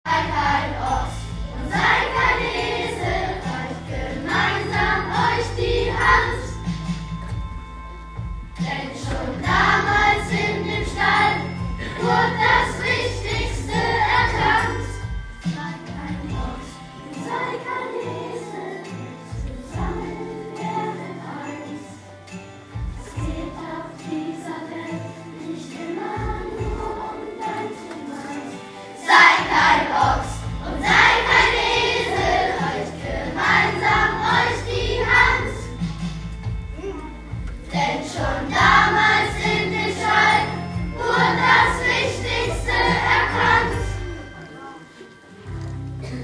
Mit einigen Eindrücken von unserer Weihnachtsfeier verabschieden wir uns für dieses Jahr und wünschen allen ein frohes, gesegnetes Weihnachtsfest und alles Gute für das kommende Jahr 2025!